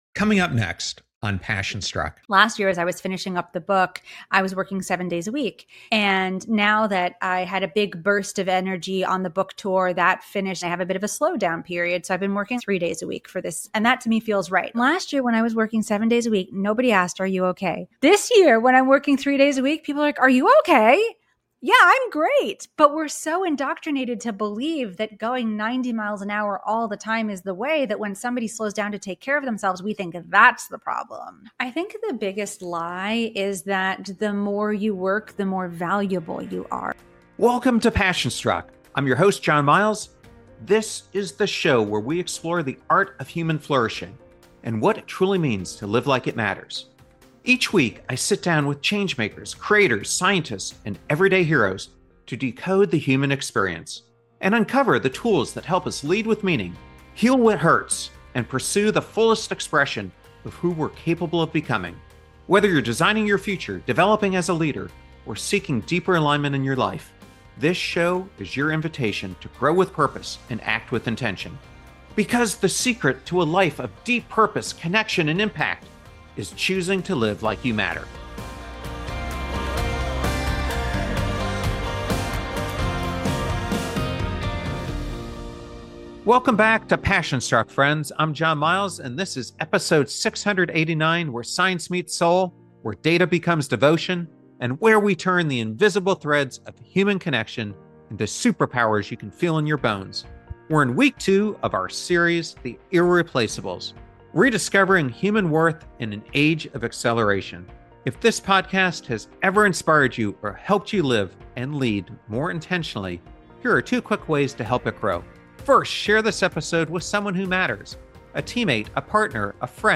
In this deeply reflective and transformative conversation